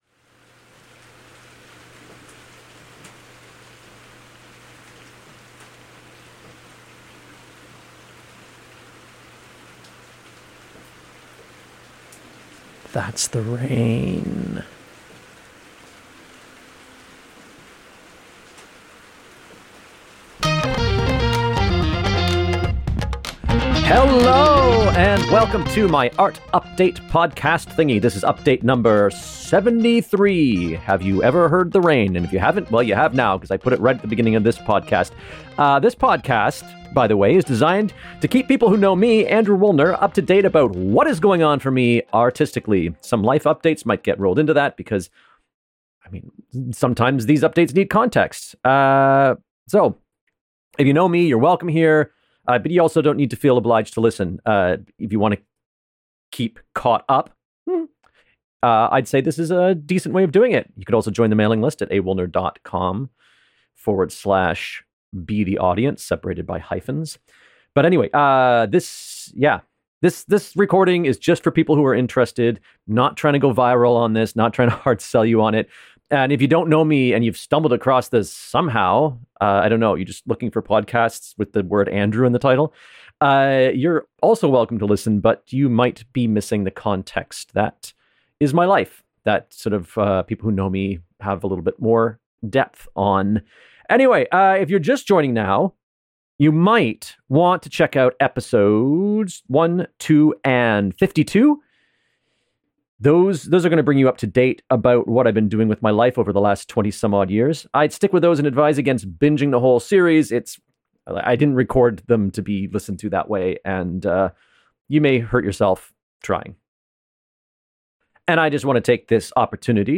This week: listen to some rain, I'm sick, a key ensemble member leaves, and two dear friends drop into rehearsal for a visit!